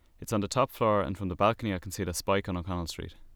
Dublin accent